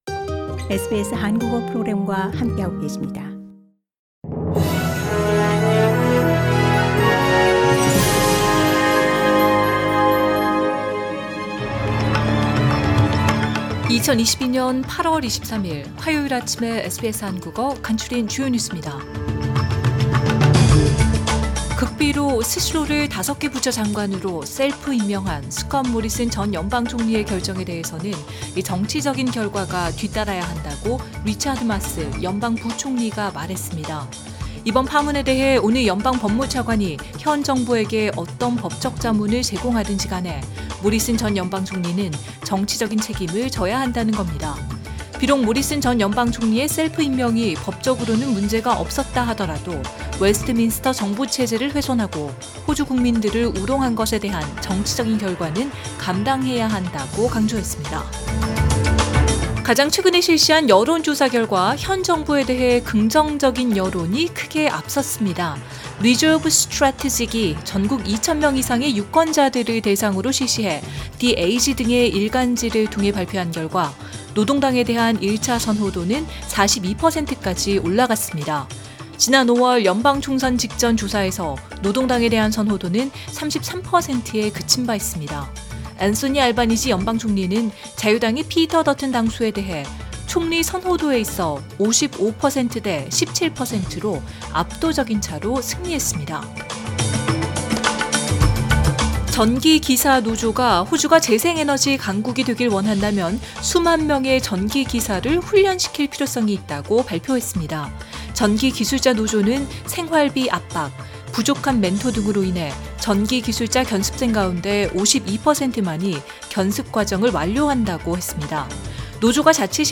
2022년 8월 23일 화요일 아침 SBS 한국어 간추린 주요 뉴스입니다.